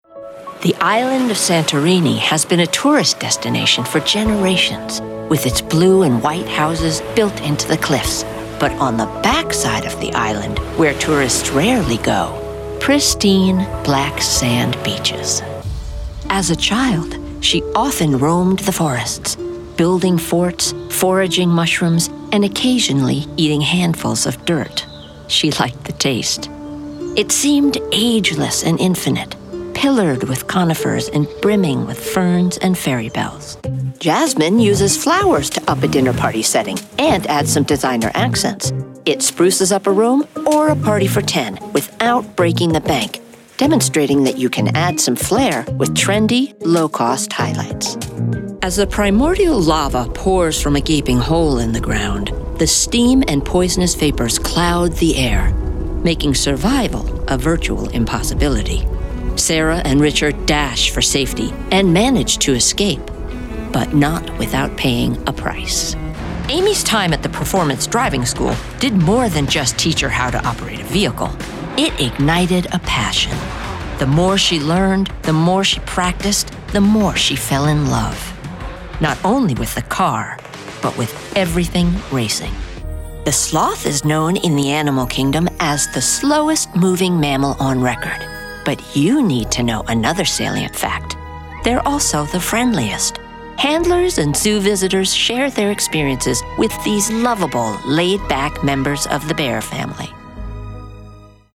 For commercial, documentary, or narrative projects seeking both authority and authenticity, my voice is versatile, warm, and compelling.
NARRATION